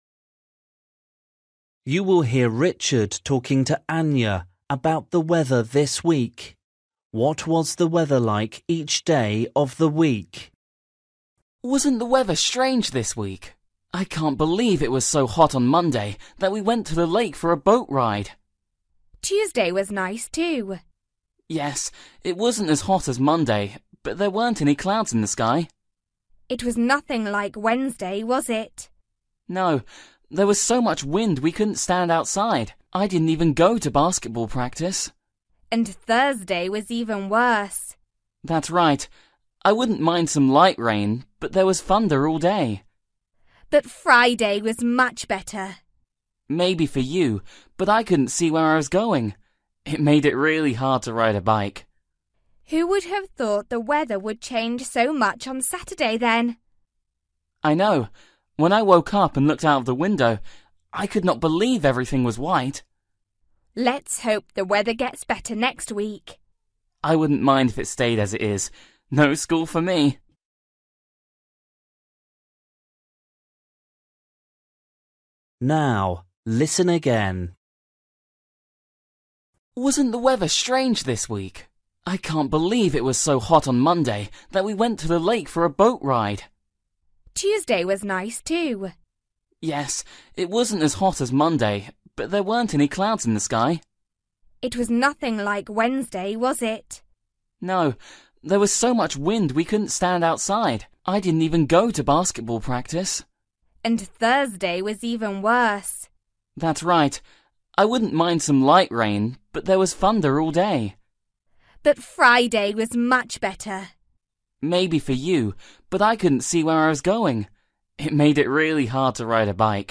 Bài tập luyện nghe tiếng Anh trình độ sơ trung cấp – Nghe cuộc trò chuyện và chọn câu trả lời đúng phần 26